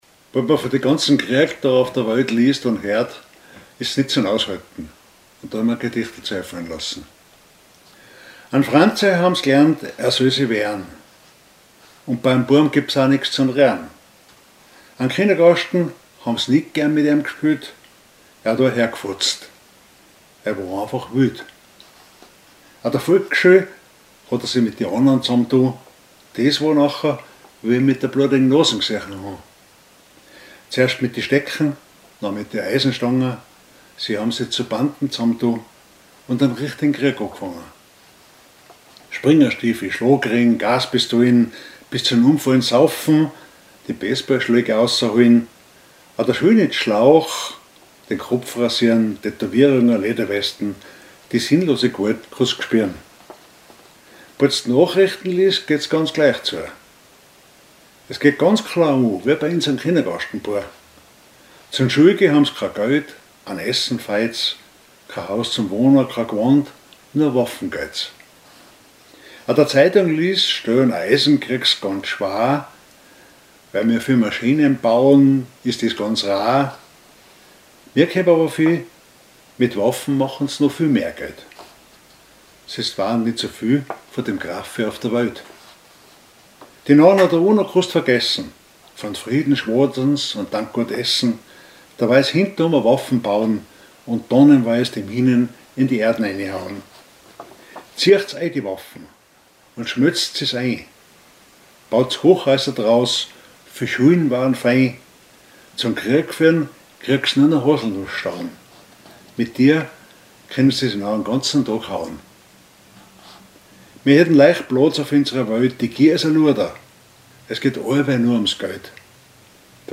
Mundart
100 Mehr Hoselnussstauden ein Gedicht gegen die Gewalt
Type: nachdenklich, sarkastisch